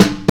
Snare (40).wav